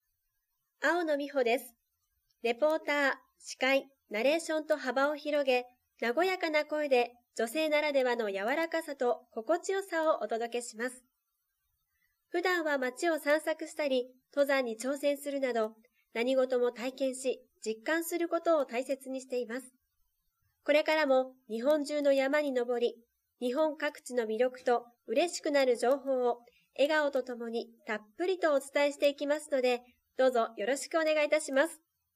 ボイスサンプル
ボイス１